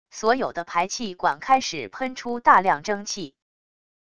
所有的排气管开始喷出大量蒸汽wav音频